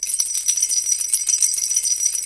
Index of /90_sSampleCDs/E-MU Producer Series Vol. 7 – Old World Instruments (CD 2)/Drums&Percussion/Bell Rattle
BEL RATTL04R.wav